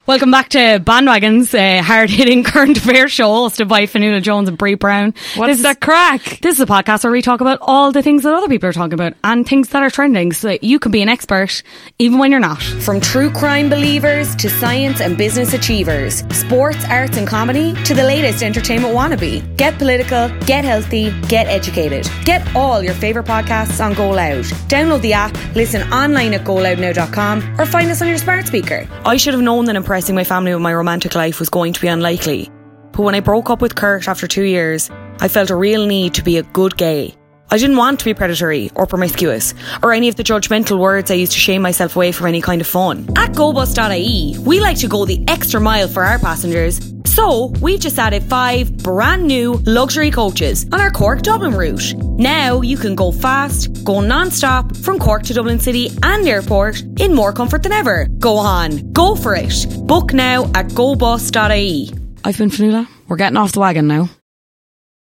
Female
20s/30s
Irish Cork